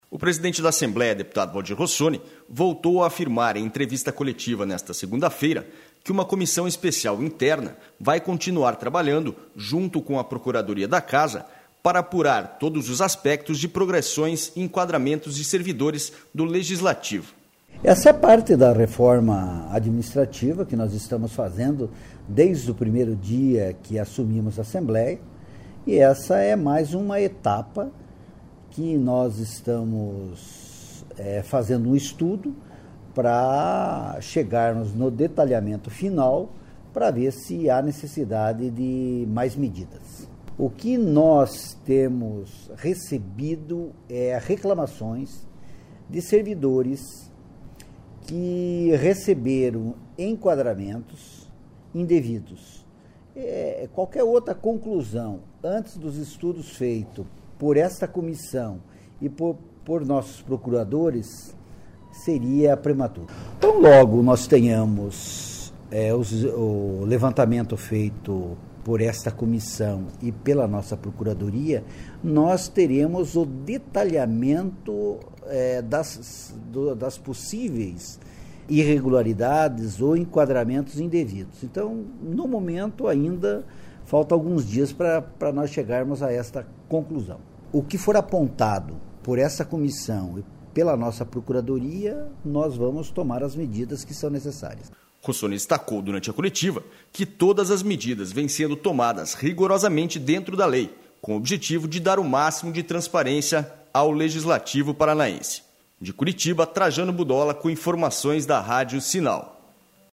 O presidente da Assembleia, Deputado Valdir Rossoni, voltou a afirmar em entrevista coletiva nesta segunda-feira, que uma comissão especial interna vai continuar trabalhando, junto com a Procuradoria da Casa, para apurar todos os aspectos de progressões e enquadramentos de servidores do Legislativo.//